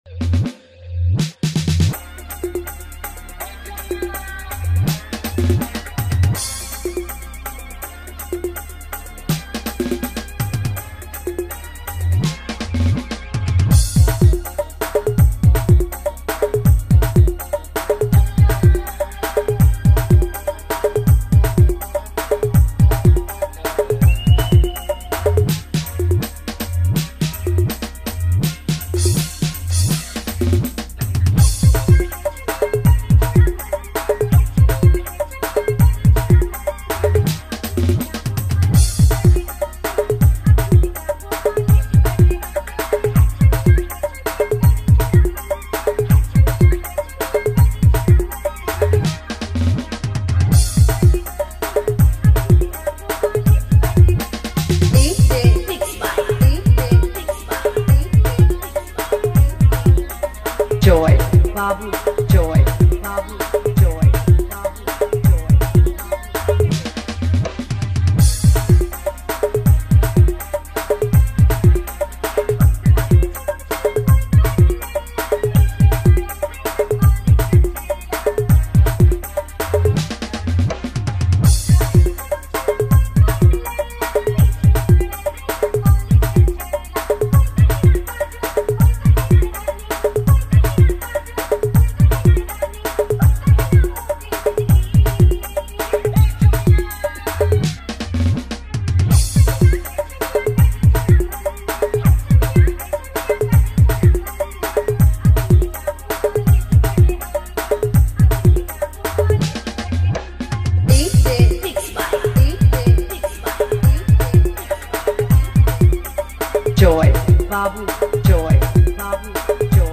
All Dj Remix